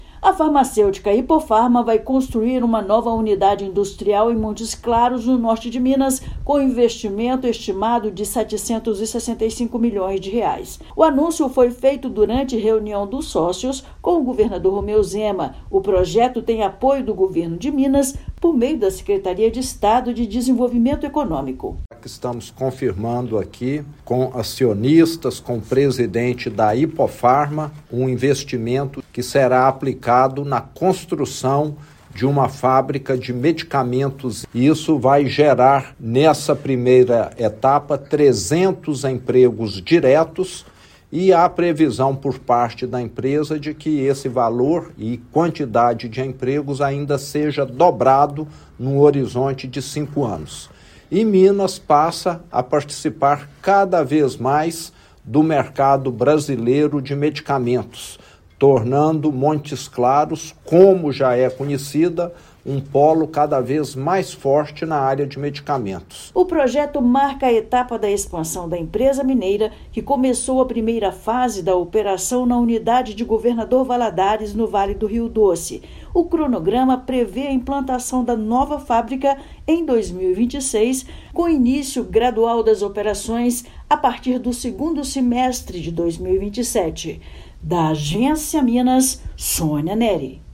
Projeto prevê a construção de nova unidade industrial em Montes Claros, gerando 250 empregos diretos. Ouça matéria de rádio.